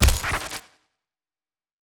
CRT_IMPACT.wav